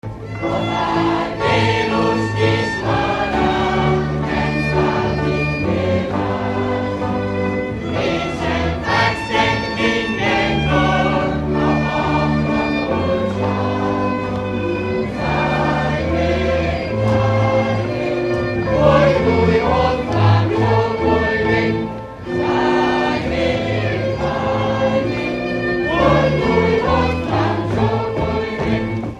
Felföld - Komárom vm. - Martos
Műfaj: Gyertyástánc
Stílus: 6. Duda-kanász mulattató stílus